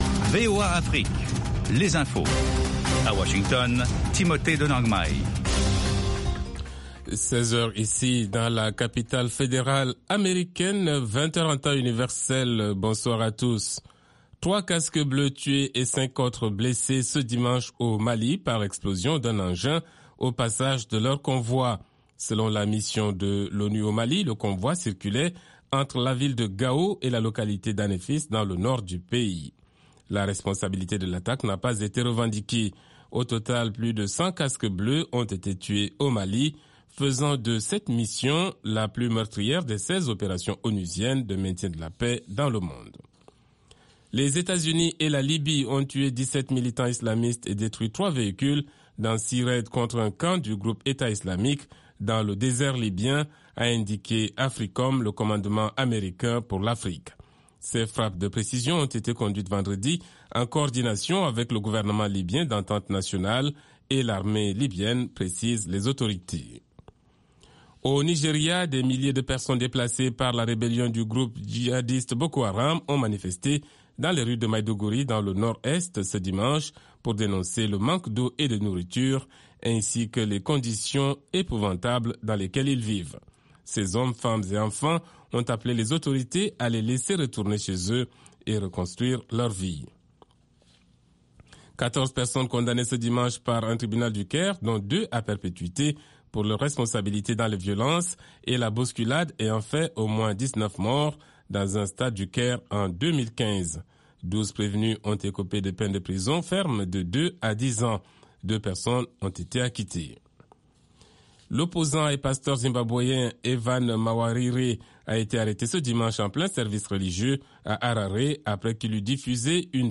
RM Show - French du blues au jazz